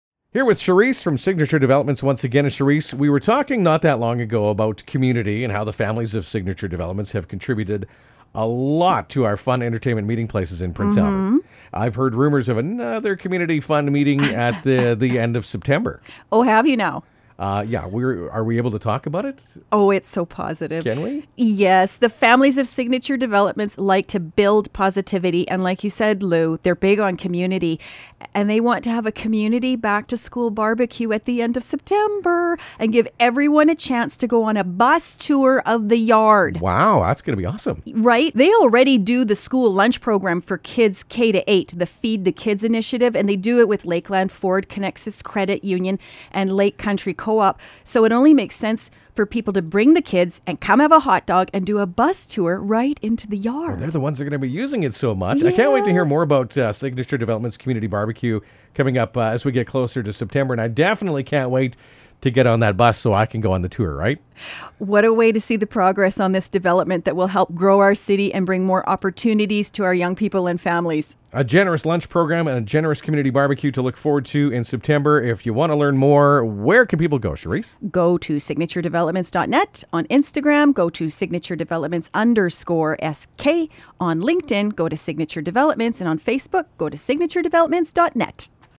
He leaked some details about the Community BBQ on the radio a few weeks back: